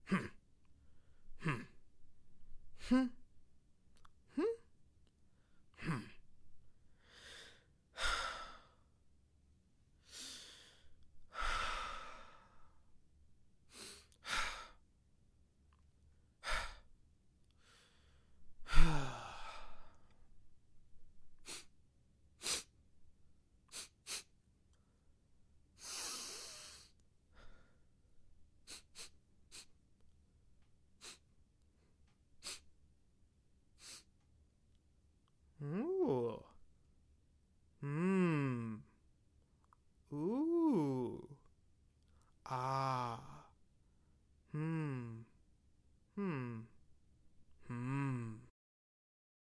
杂项声音
描述：一个男人的各种声音，包括咕噜声，叹息声，哼声声，可以用作沮丧，惊讶或震惊的人。
Tag: OWI 男子的呻吟声 惊奇 兴趣 无奈 感叹声 呼噜声 震撼 酣畅 呼吸